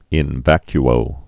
(ĭn văky-ō)